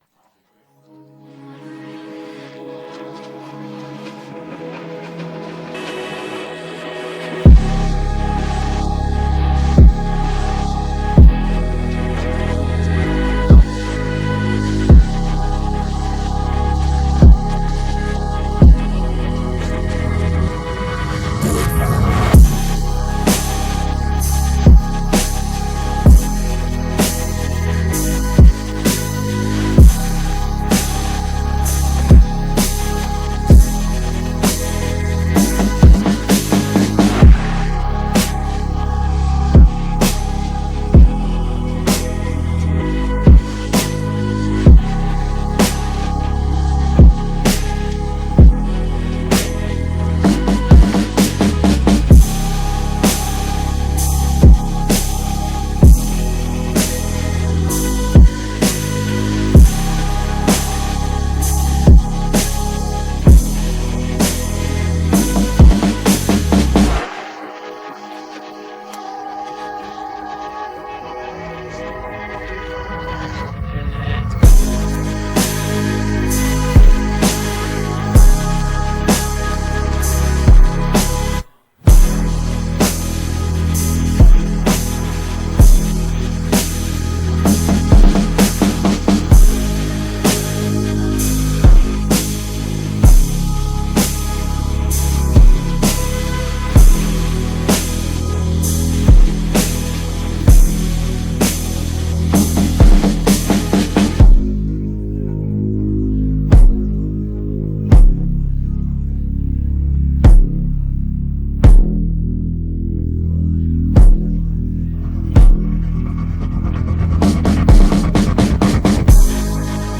караоке инструментал